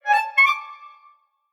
tiptoes.mp3